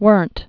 (wûrnt, wûrənt)